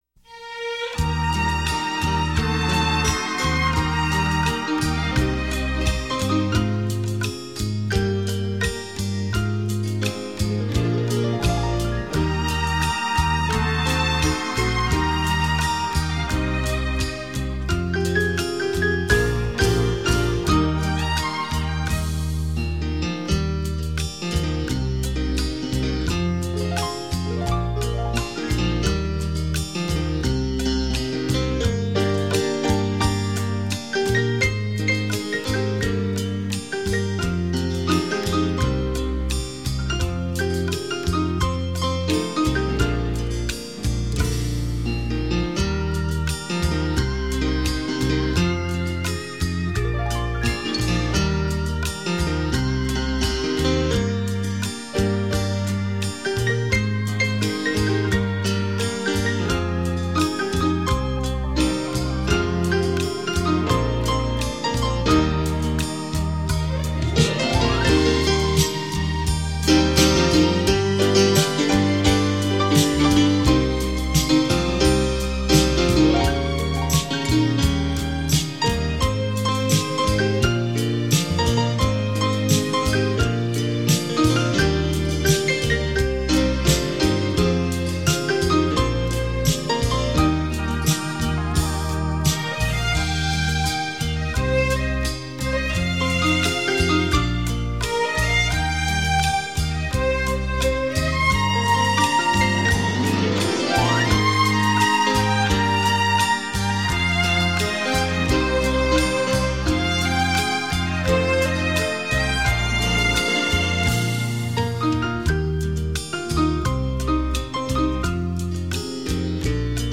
双钢琴的震撼，与您体验琴乐声交织而成的文化艺术飨宴。